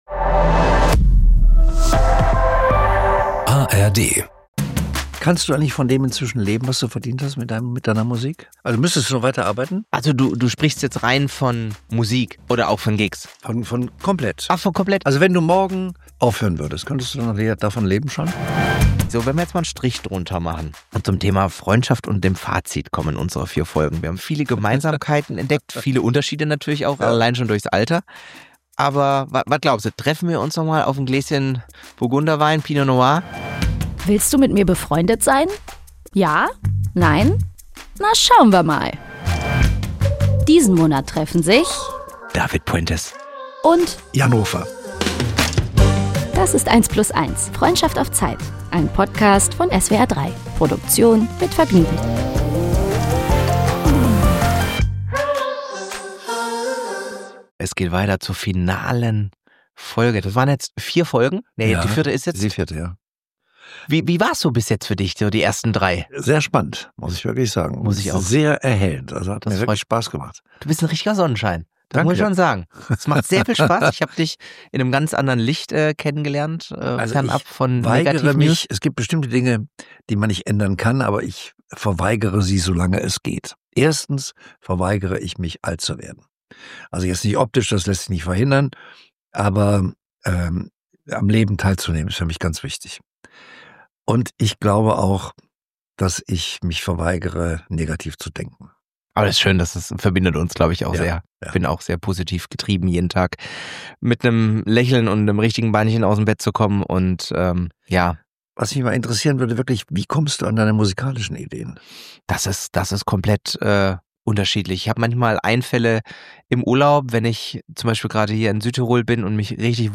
Jeden Monat ein neues Duo: Hier treffen sich immer mittwochs zwei ziemlich bekannte Menschen und versuchen beste Freund*innen zu werden – mal sind die Zwei sich super ähnlich, mal könnten sie nicht unterschiedlicher sein. Am Ende stellt sich dann immer die große Frage: Willst du mit mir befreundet sein?
… continue reading 151 епізодів # Gesellschaft # SWR3 # Promis&stars # Comedy # Interview # Gespräche # Promis